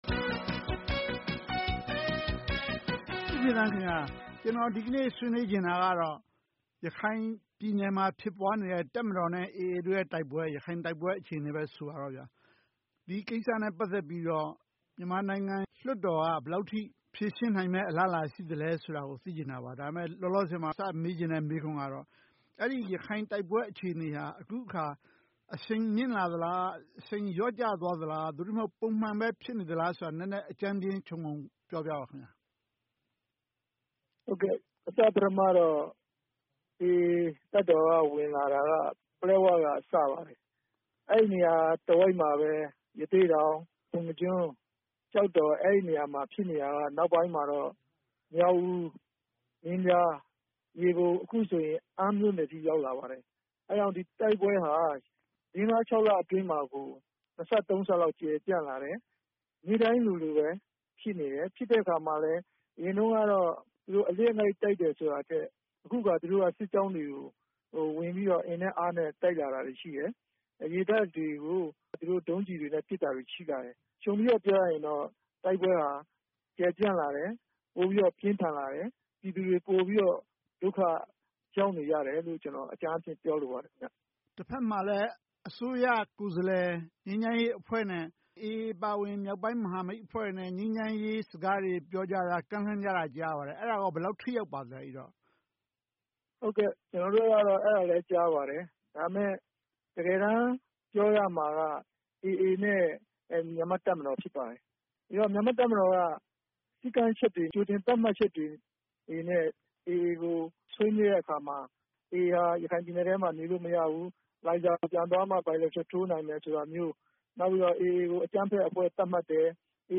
ဆွေးနွေးသုံးသပ်ထားပါတယ်။